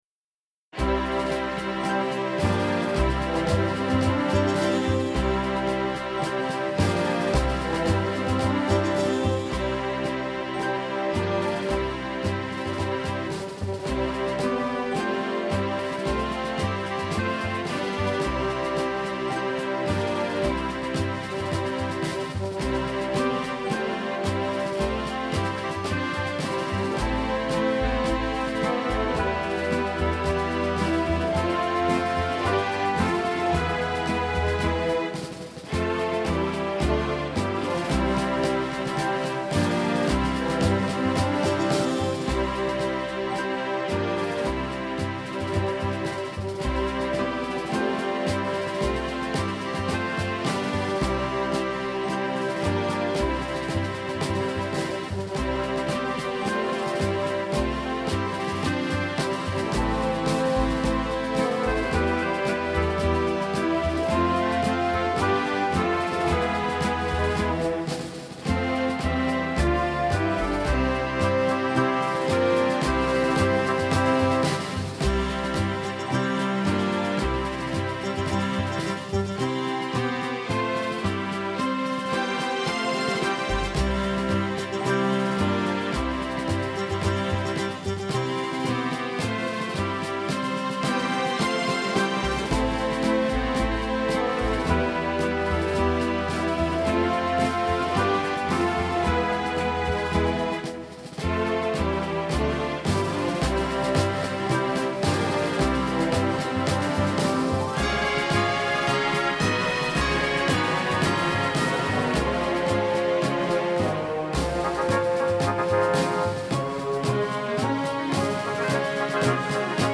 Genre: Holiday.